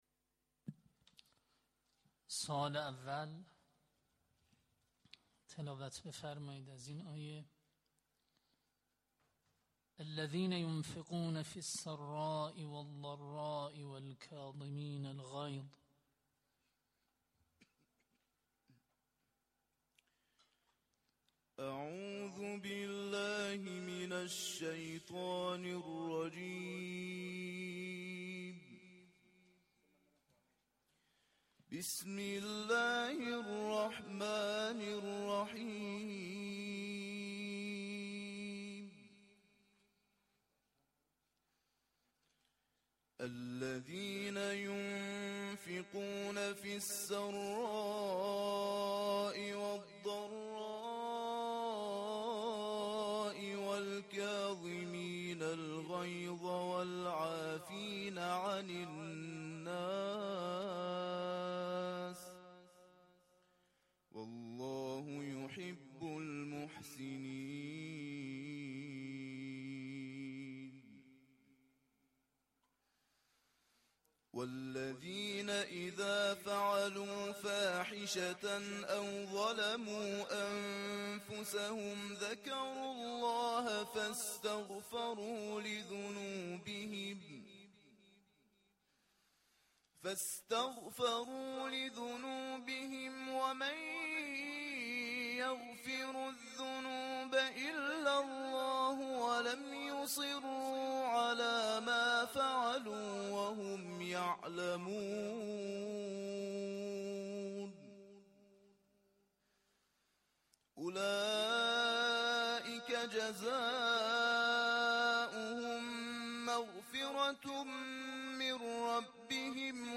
نخستین دوره مسابقات قرآن طلاب جهان اسلام با حضور نماینده 42 کشور جهان امروز یکشنبه 3 اردیبهشت‌ماه در شهر مقدس قم برگزار شد.
در ادامه صوت تلاوت تقدیم مخاطبان می‌شود.